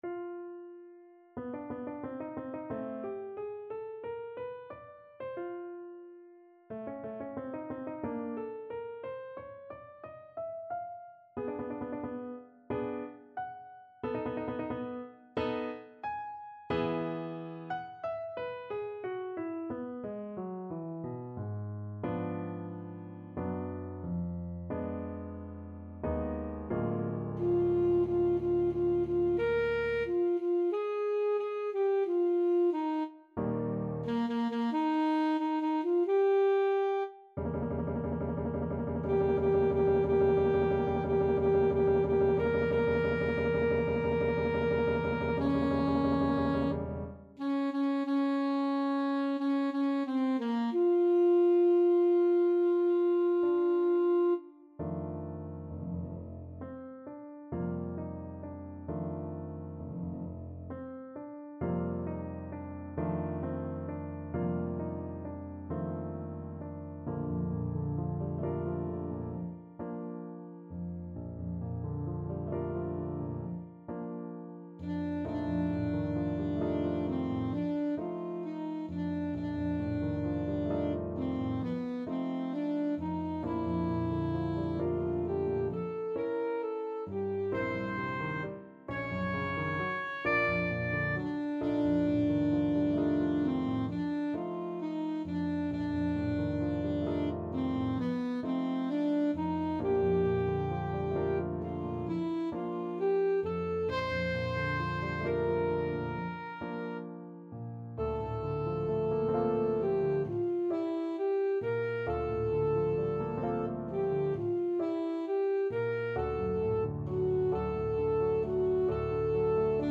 Free Sheet music for Alto Saxophone
Alto Saxophone
Moderato =90
4/4 (View more 4/4 Music)